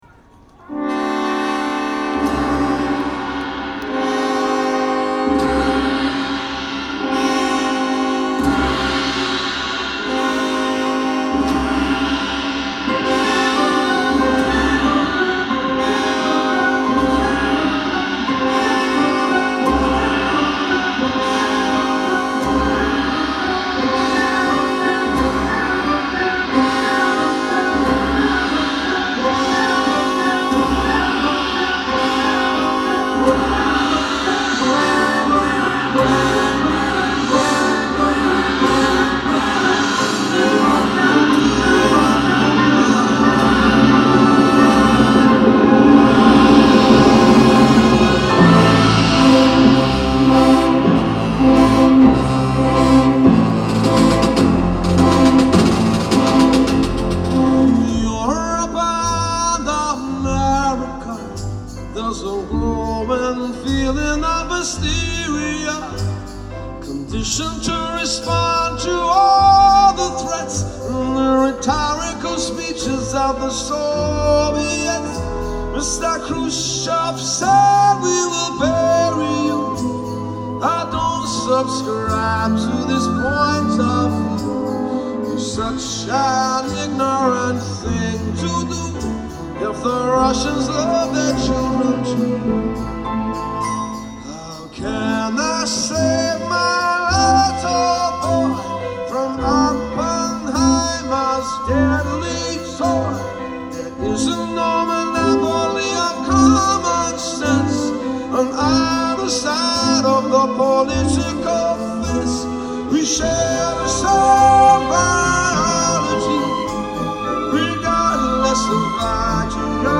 Una buona registrazione audience
vocals, guitar, harmonica
bass
percussion
trumpet